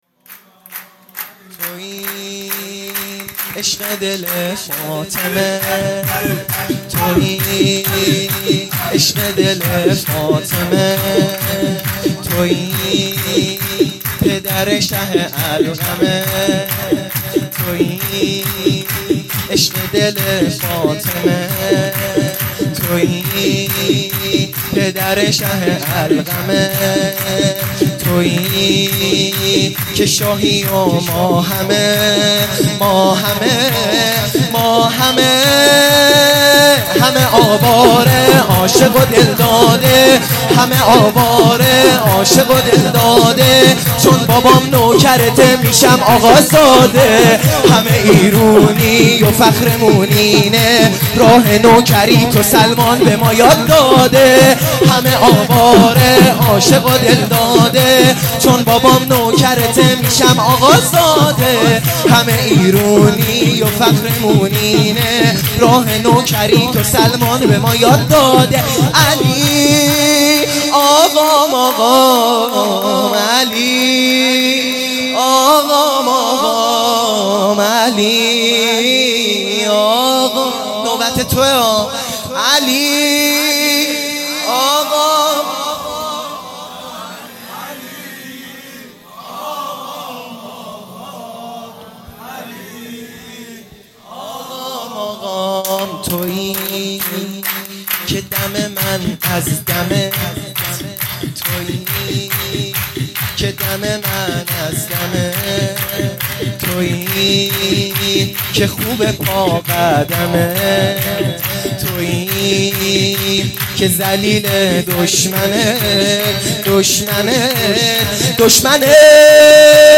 سرود ا آقازاده ام